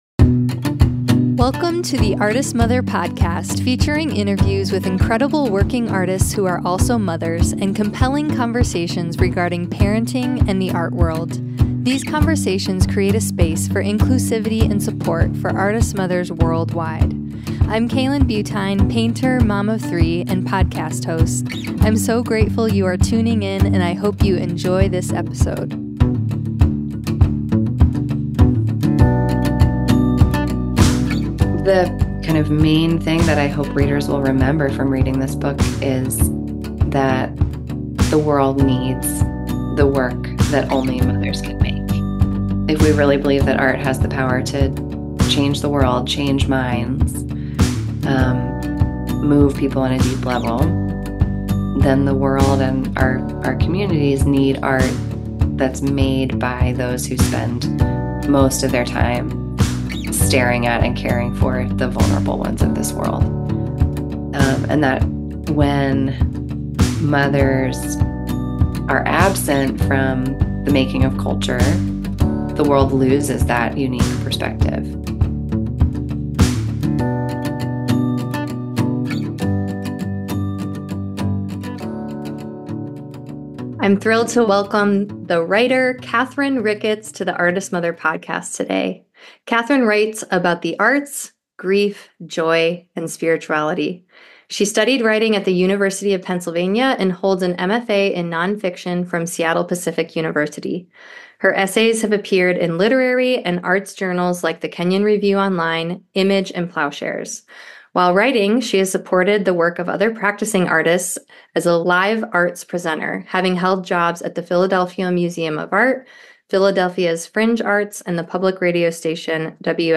The Artist/Mother podcast interviews incredible working Artists who are also Mothers, inviting them to share from their experiences as they make art and mother their children.